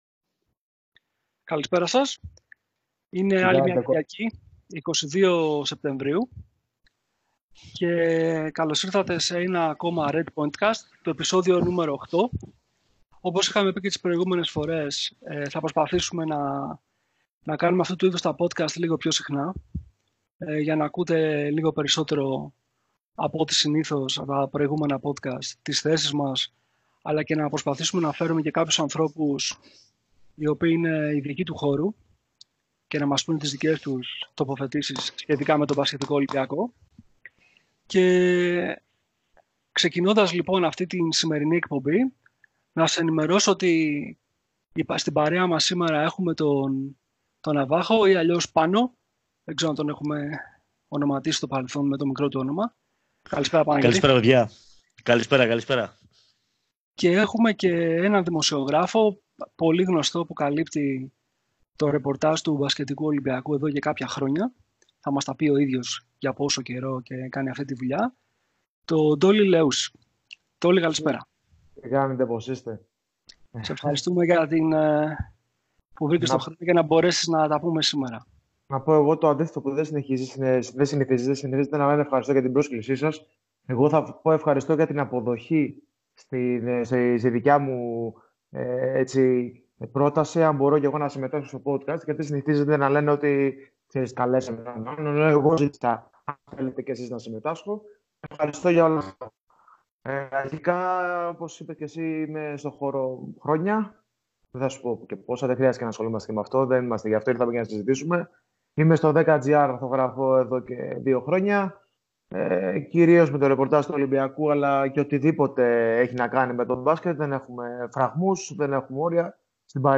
Η ερασιτεχνική μας παραγωγή έχει κάποια θέματα με τον ήχο στο 1¨06, 1¨18 και λίγο πριν το τέλος 1″24. Ρίξαμε την ευθύνη στον ET και στους κακούς hacker που ήθελαν να υποκλέψουν το τι λέγαμε… η αλήθεια όμως είναι πως μας πρόδωσε το VOIP και ελπίζουμε να μην σας σπάσει πολύ τα νεύρα.